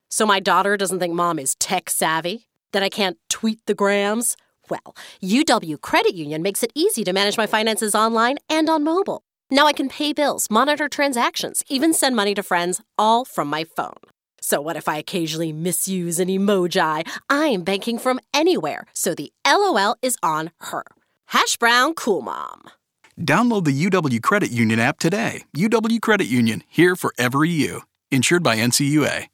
The winning radio commercial